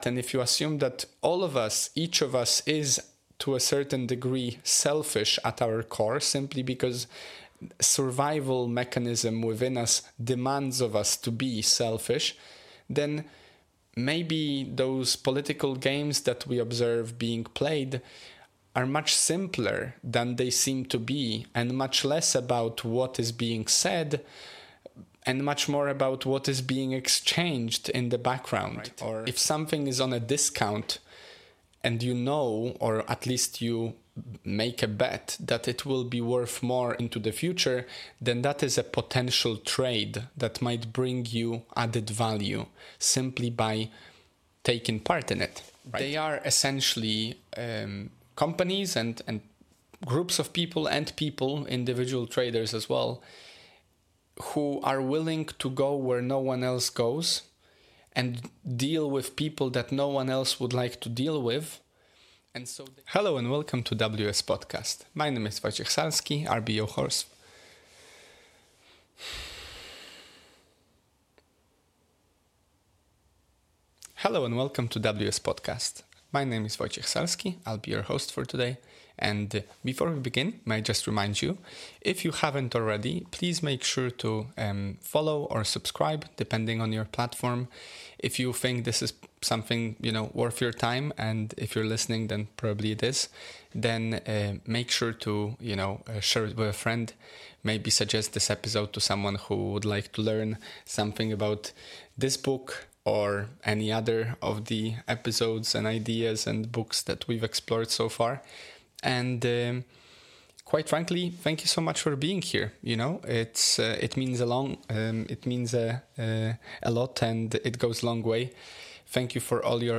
Monologue #20 - Successful Year(s) (ep. 230)